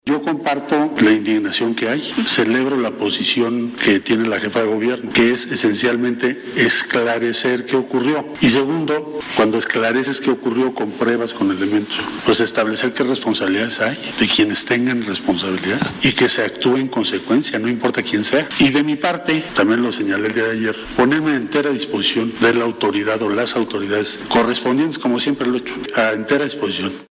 Ciudad de México, 04 de mayo del 2021.- El secretario de Relaciones Exteriores, Marcelo Ebrard, dijo durante la conferencia mañanera de este martes, que se pondrá a “entera disposición” de las autoridades correspondientes, en la investigación del accidente que se registró la noche del lunes en la Línea 12 del Metro.